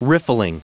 Prononciation du mot riffling en anglais (fichier audio)
Prononciation du mot : riffling